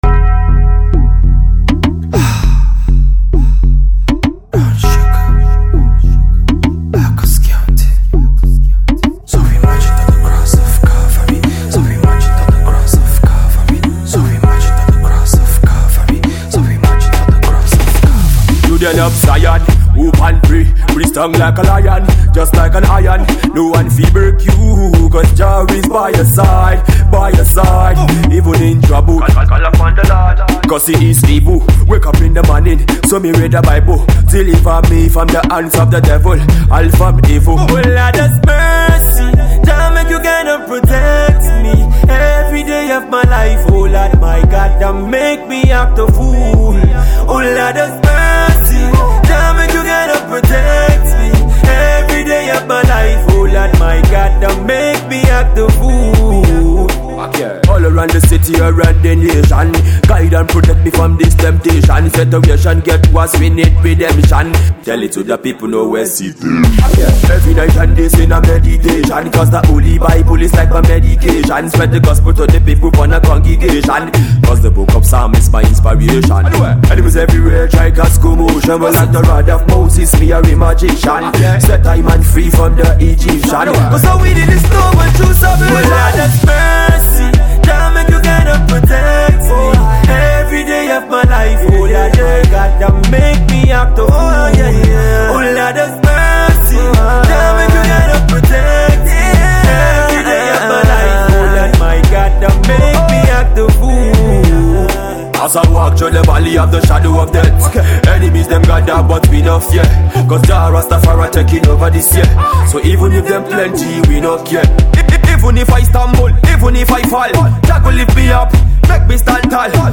While the catchy crooning Afro Pop/dancehall act
patois flavour